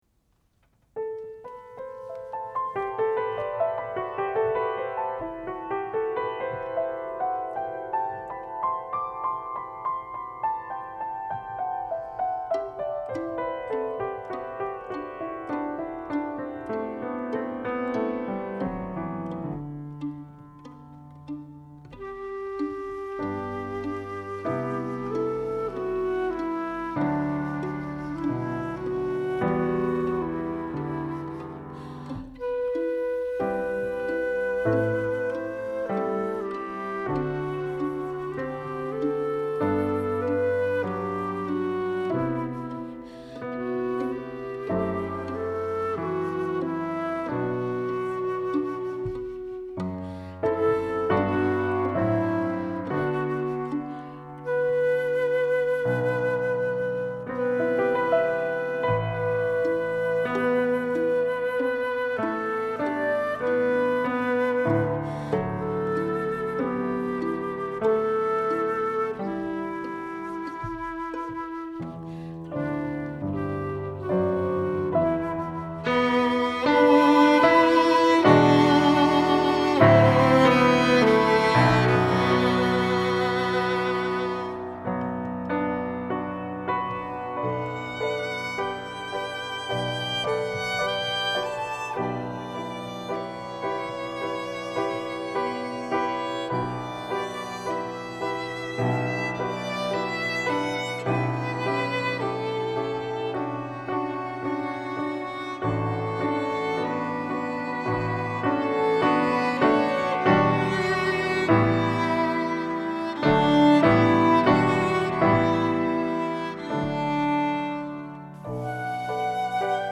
Parakaleo Chamber Concert
Coppell Music Conservatory Recital Hall
Arranged by Kazoo Tokito
Pavane pour une infante défunte for Flute, Violin and Piano